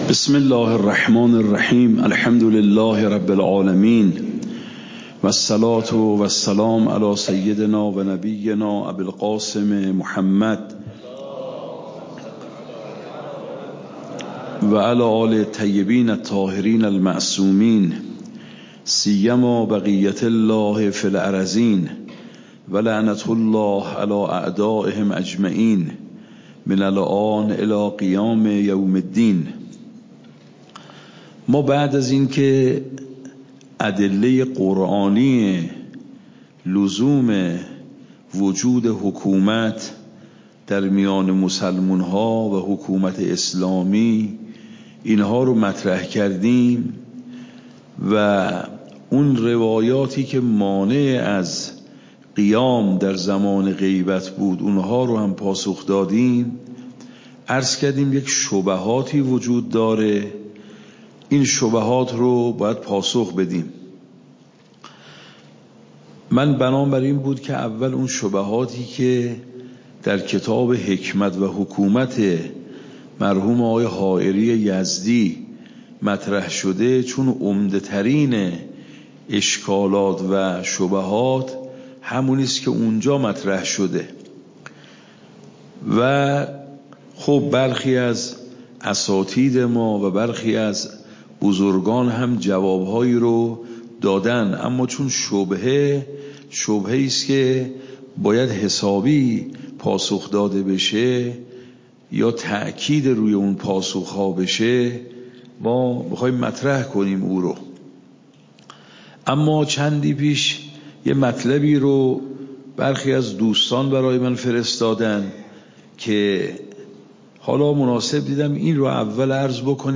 آیات حکومت در قرآن (ادله منکرین حکومت) (4) فقه خارج